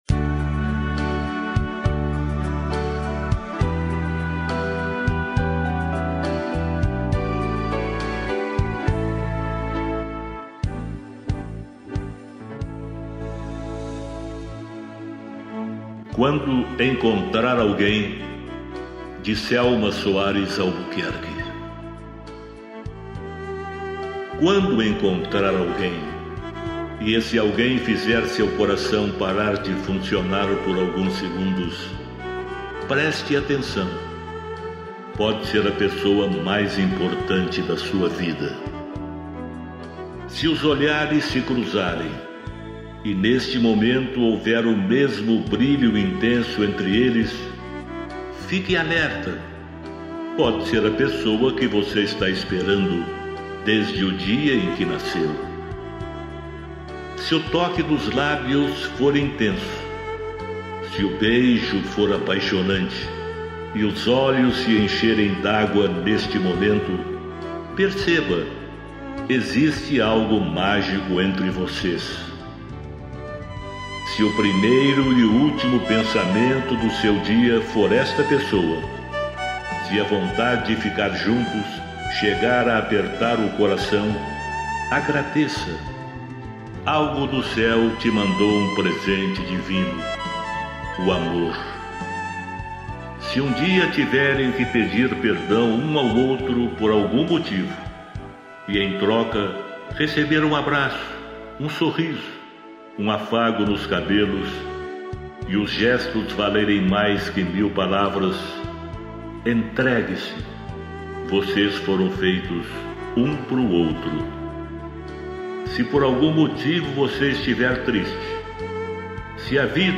Poemas de vários poetas interpretados